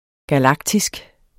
Udtale [ gaˈlɑgtisg ]